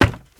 High Quality Footsteps
Wood, Creaky
STEPS Wood, Creaky, Run 29.wav